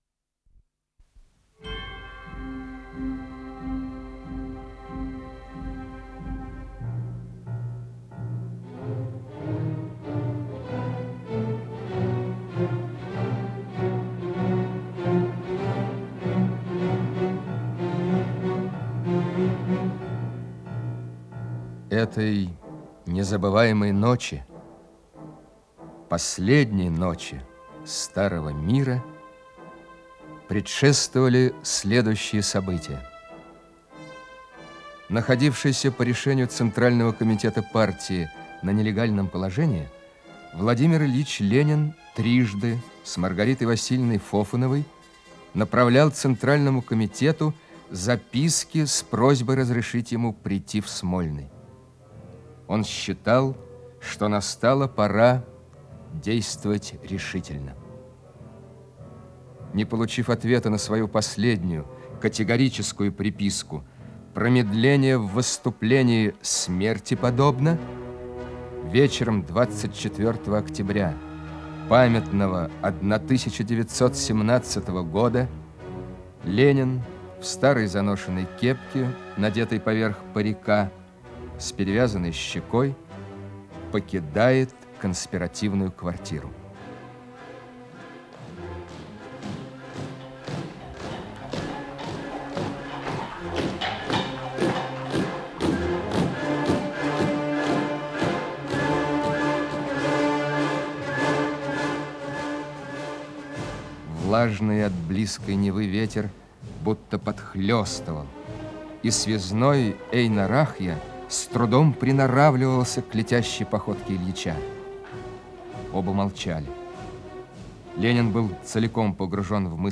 Радиоспектакль